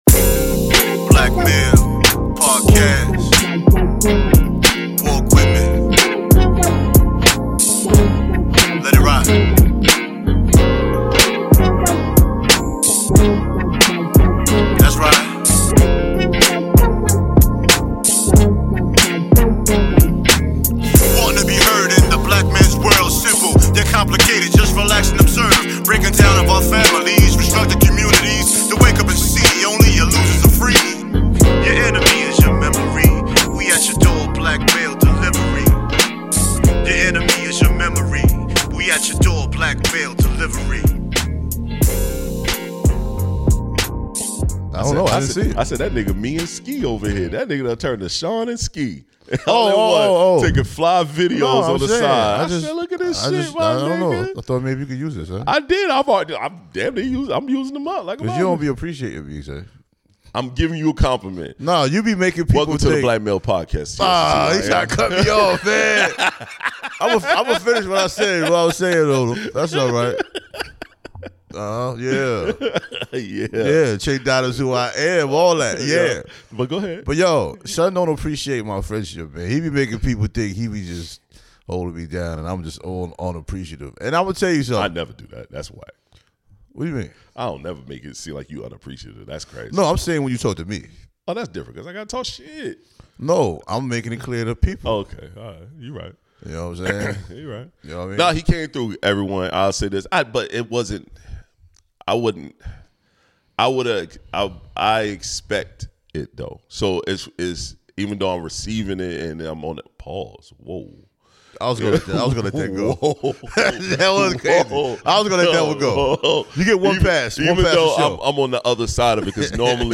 They engage in meaningful conversations about challenges, triumphs, and what it takes to empower their community.